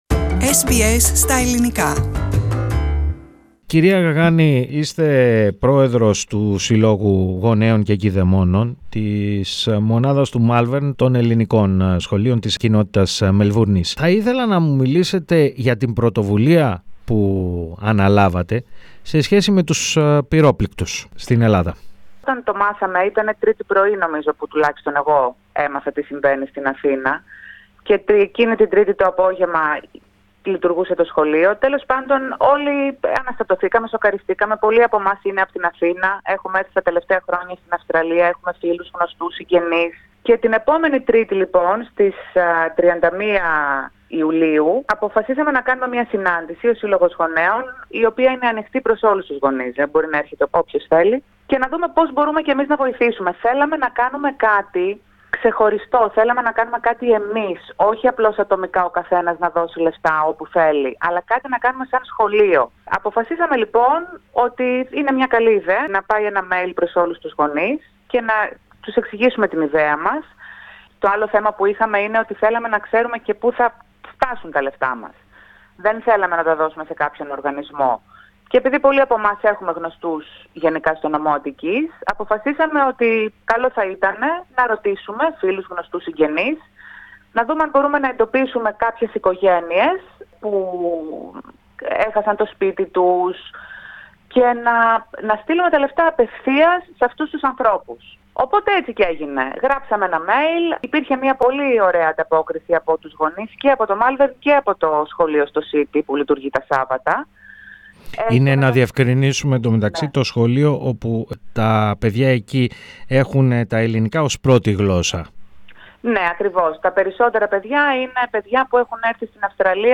Πατήστε Play στο Podcast που συνοδεύει την κεντρική φωτογραφία για να ακούσετε τη συνέντευξη.